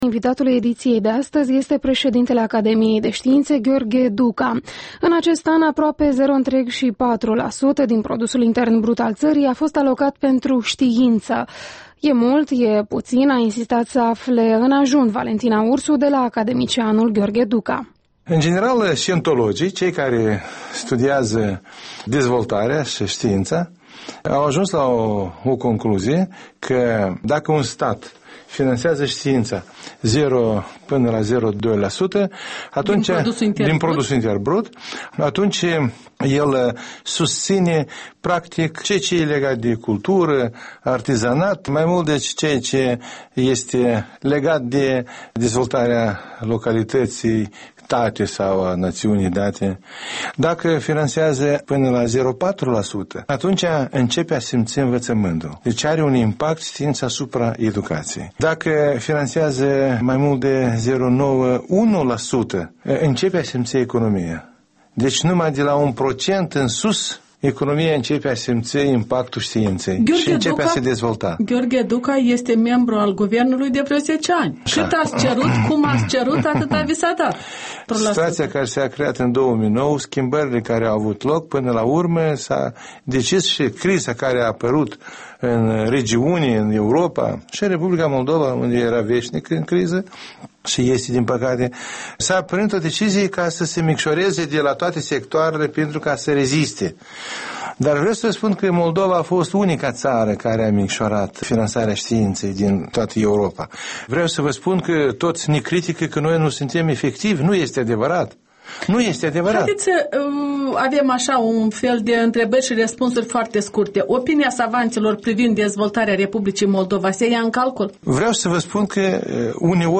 Interviul dimineții la EL: cu Gheorghe Duca, președintele Academiei de Științe